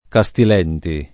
[ ka S til $ nti ]